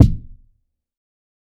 TC Kick 19.wav